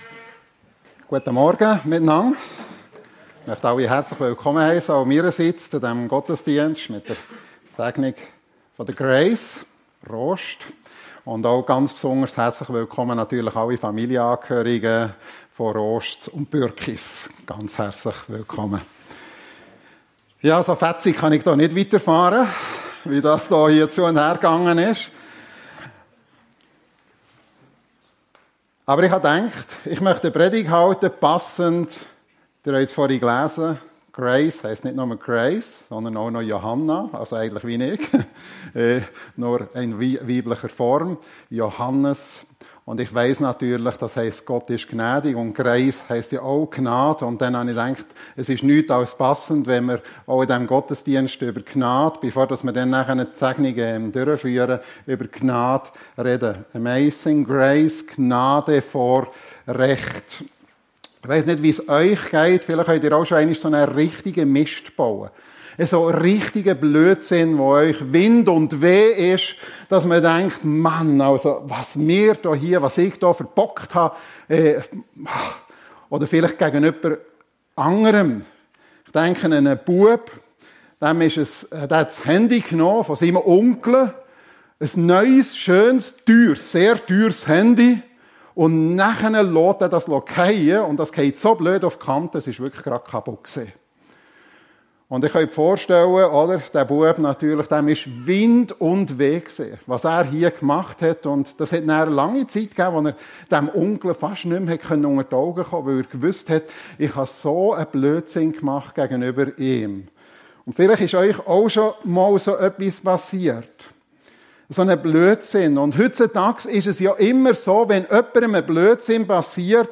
Amazing Grace - Gnade vor Recht ~ FEG Sumiswald - Predigten Podcast